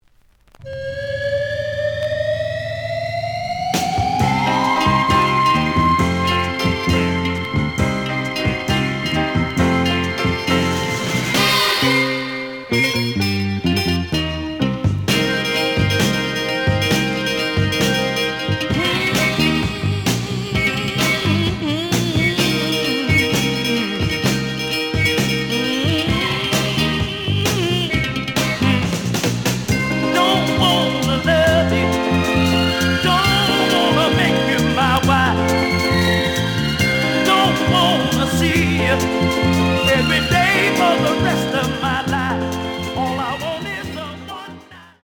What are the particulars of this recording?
The audio sample is recorded from the actual item. Some click noise on A side, but almost good.)